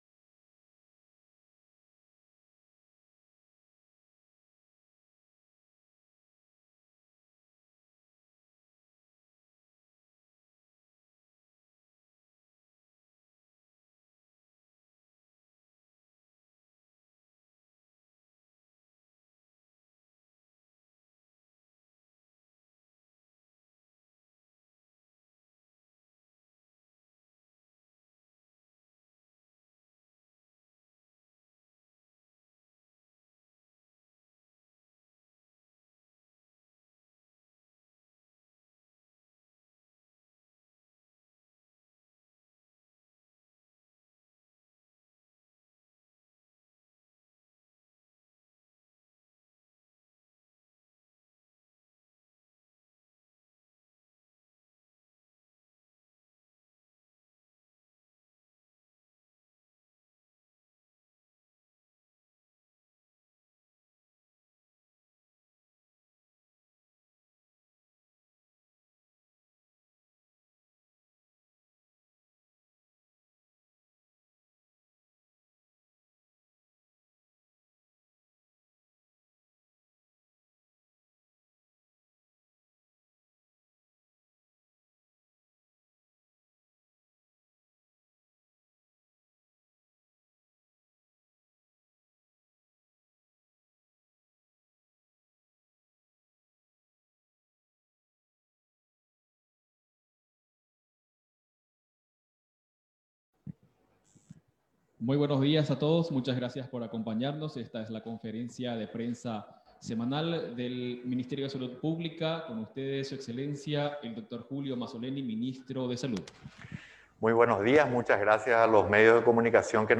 19-CONFERENCIA-JULIO-MAZZOLENI-COVAX.mp3